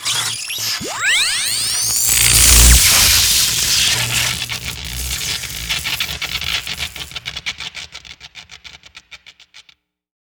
lightn1.wav